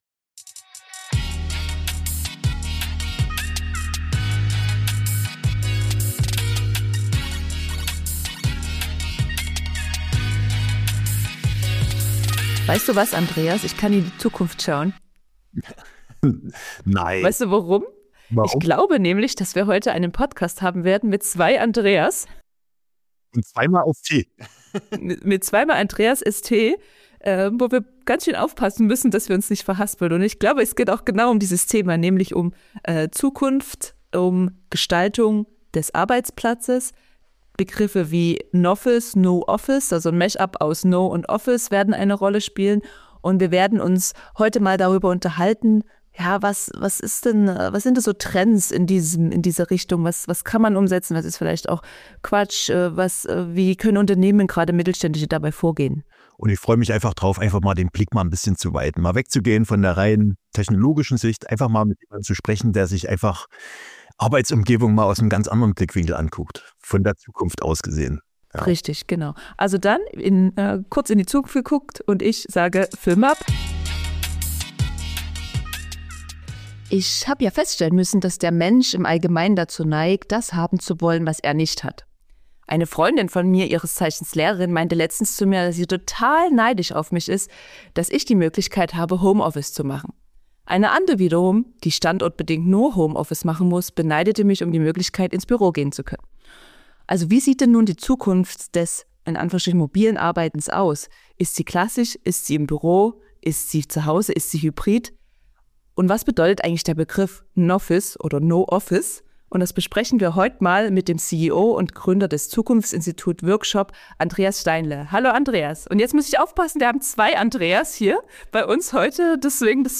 Expertengespräch: Homeoffice war nur der Anfang: Ist NOffice die neue Meta? ~ Time4Work Podcast